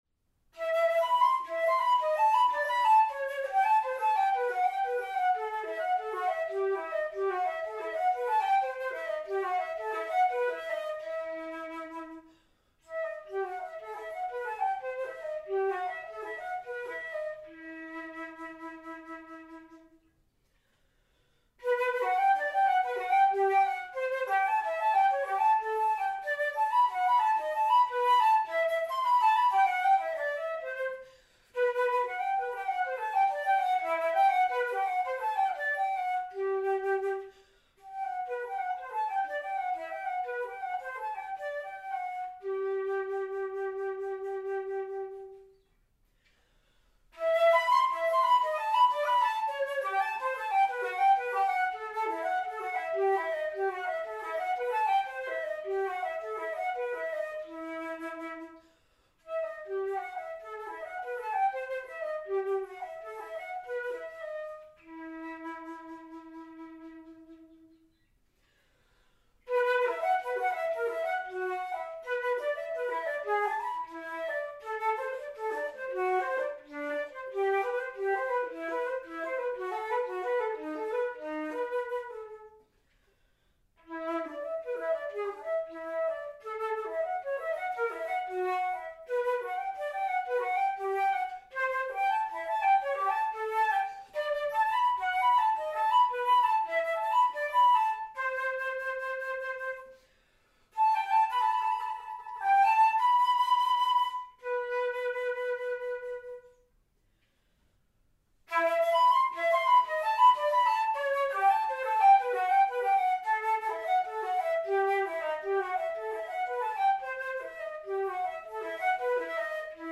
Michel Blavet: Gigue en Rondeau, and Rondeau for solo flute.
using a Zoom Handy6 recording device, Stellenbosch, 2020.
unedited